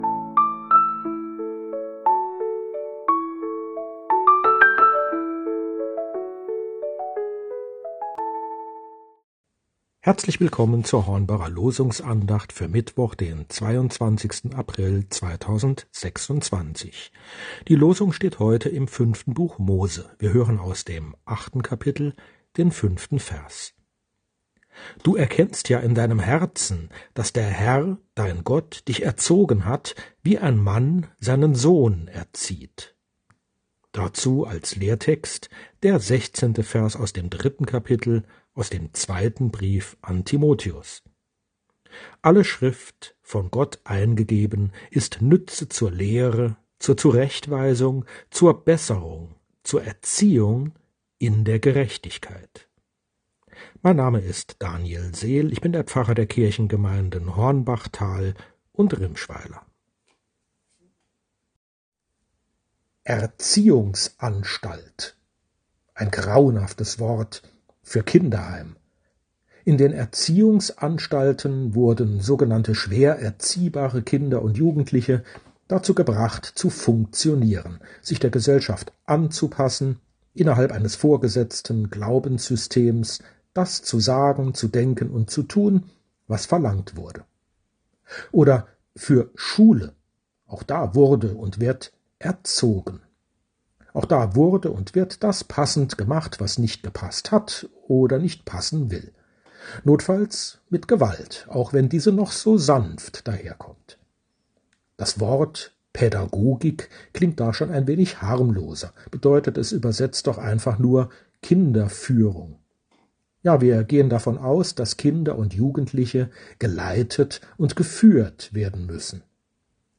Losungsandacht für Mittwoch, 22.04.2026 – Prot.